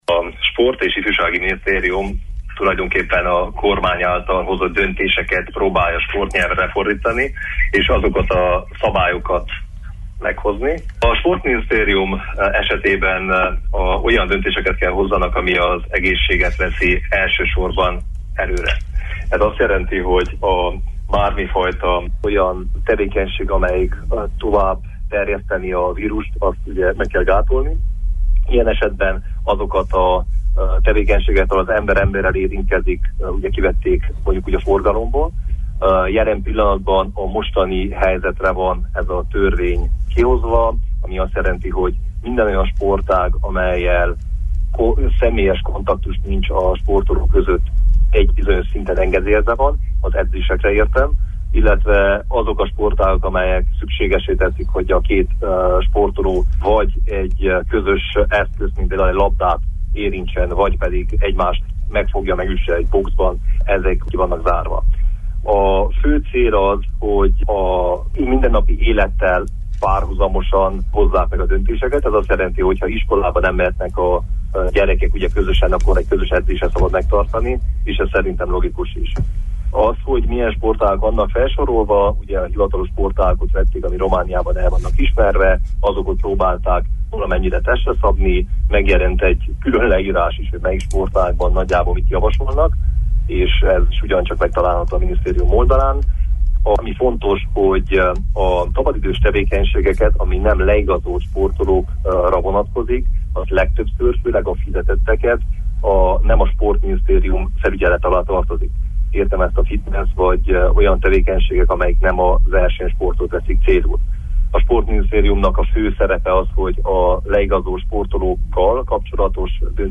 Riporter